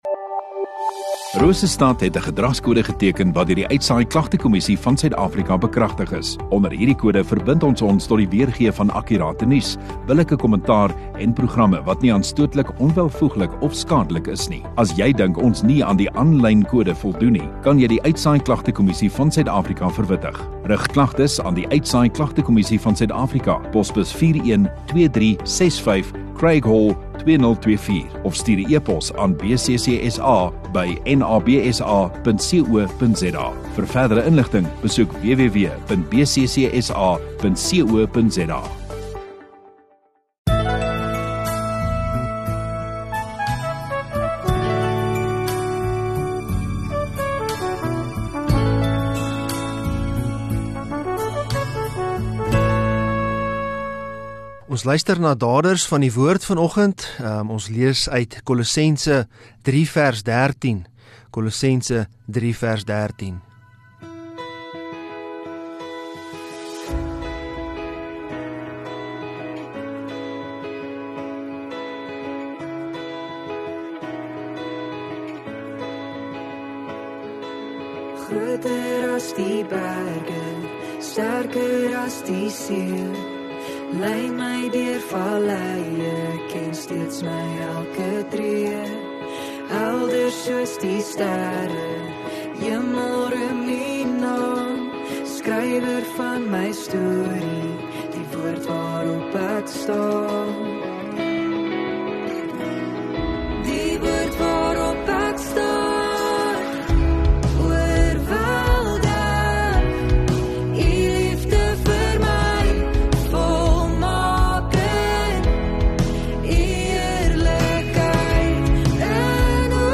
18 Jan Saterdag Oggenddiens